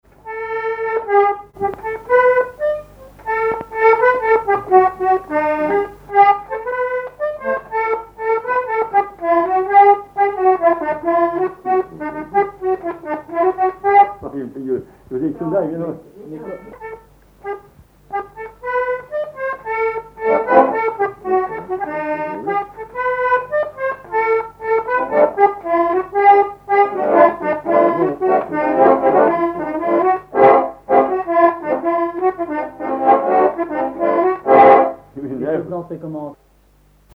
danse : polka piquée
accordéon diatonique
Pièce musicale inédite